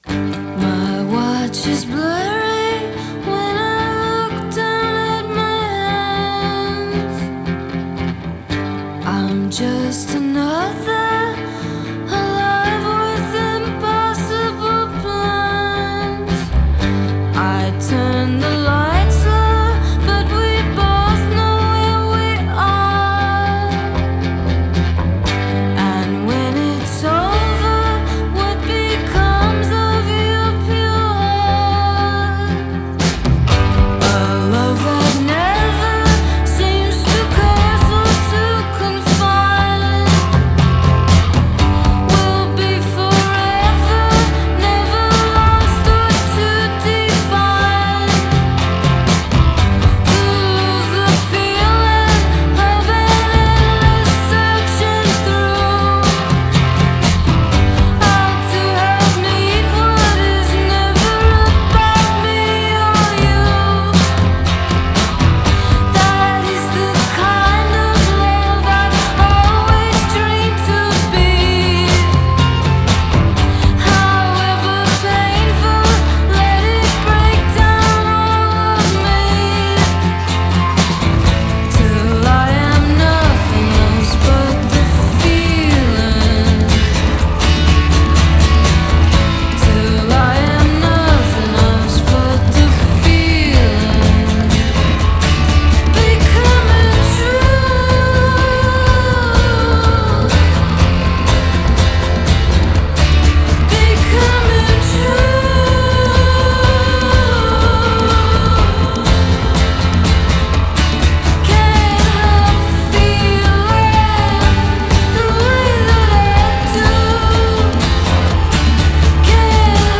lush, indie folk-rock